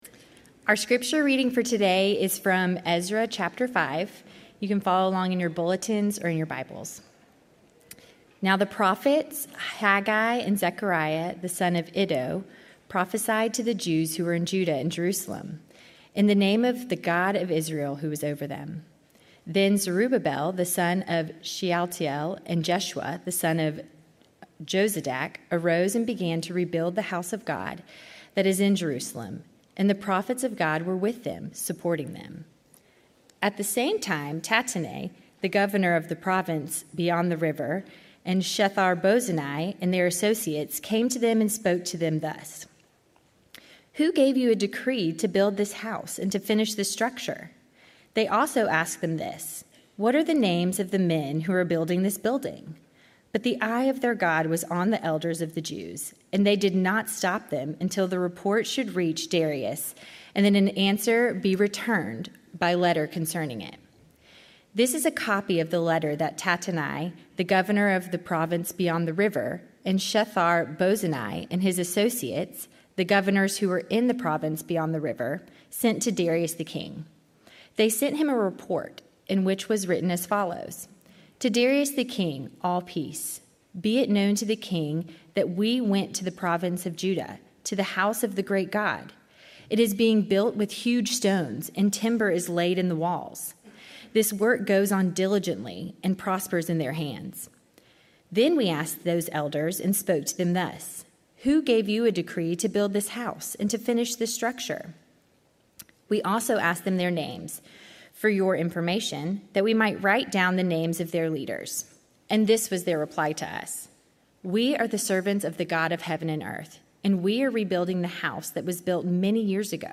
A message from the series "Ezra."